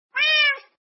meow.mp3